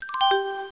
На этой странице собраны оригинальные звуки Windows 95: старт системы, уведомления, ошибки и другие знакомые мелодии.
Мелодия для Windows 95 со звоном колокольчиков